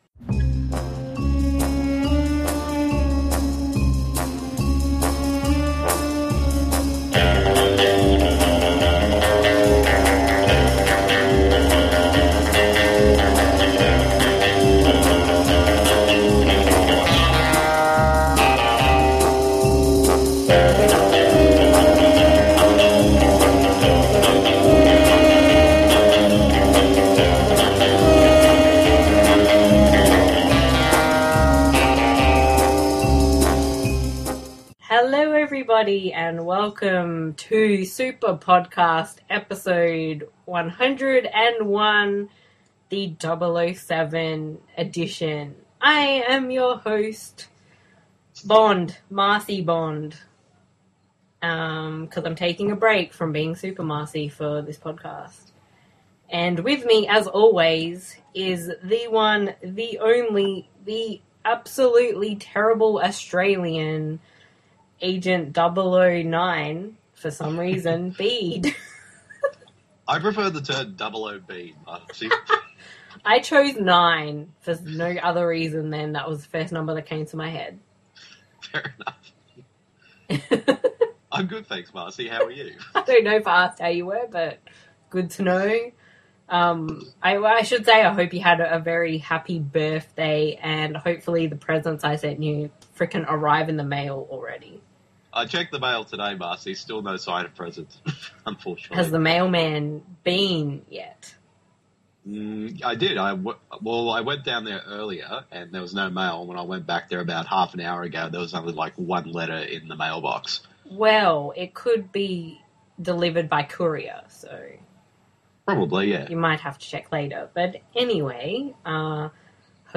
Did someone say Sean Connery impressions?